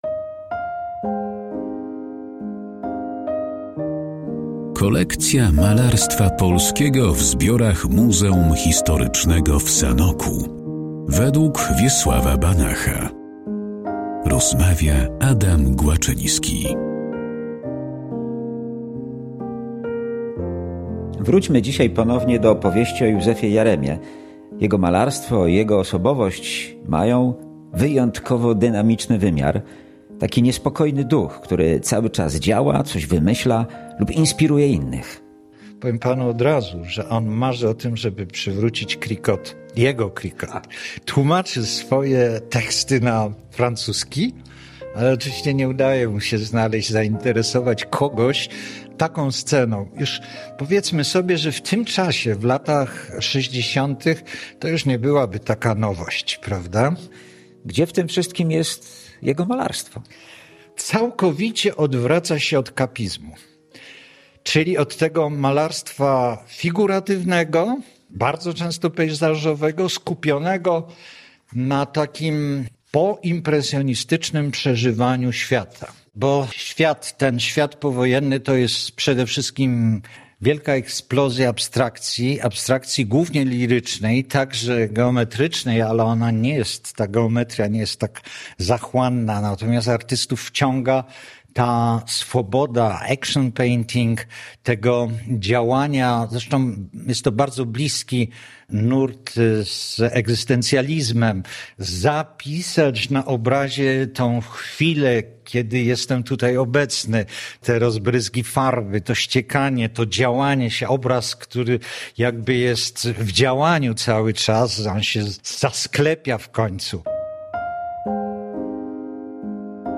O twórczości Józefa Jaremy i jej pracach znajdujących się w Muzeum Historycznym w Sanoku rozmawiają: